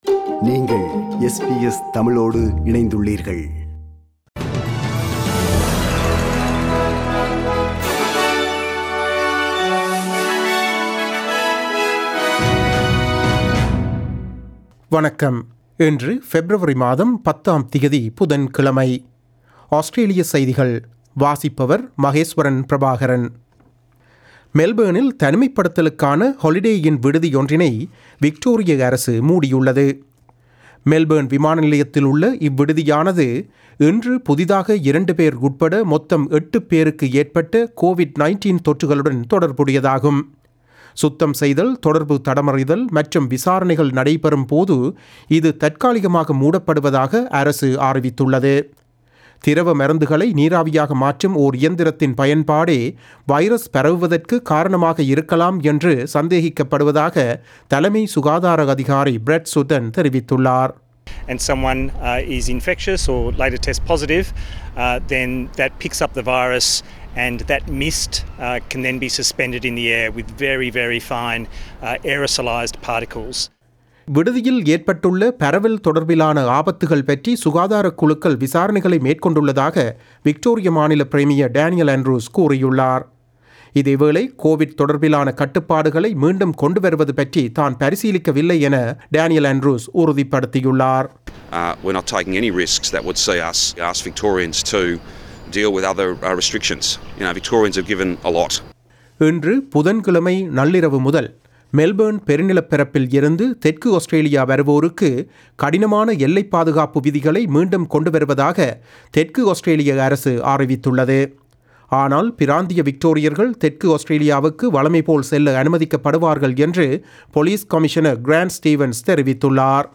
SBS தமிழ் ஒலிபரப்பின் இன்றைய (புதன் கிழமை 10/02/2021) ஆஸ்திரேலியா குறித்த செய்திகள்.